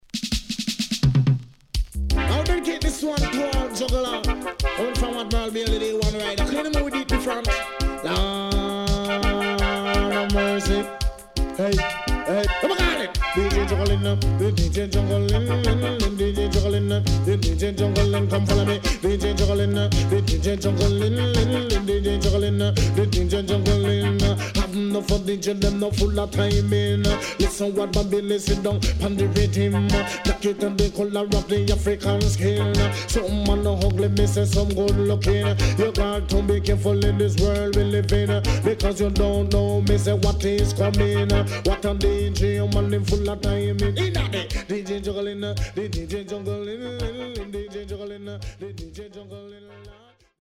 HOME > DANCEHALL